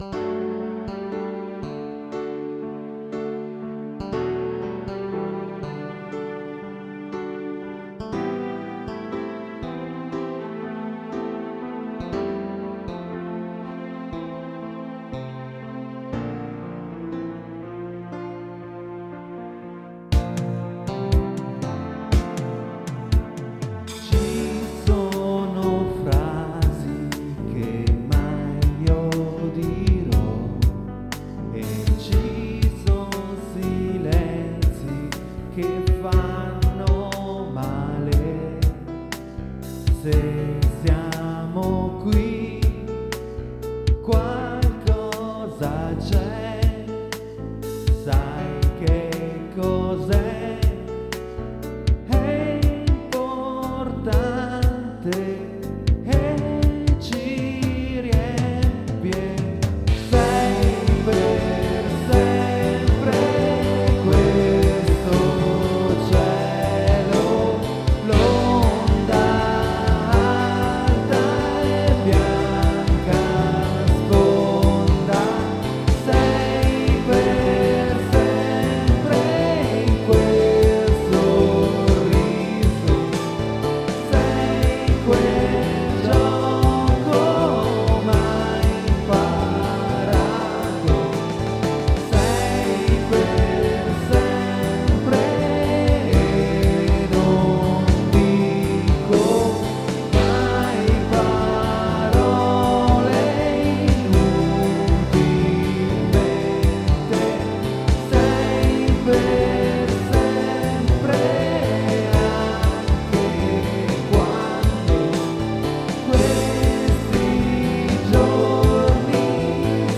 • Multitrack Recorder Zoom MRS-4
• Mic AKG D 40 S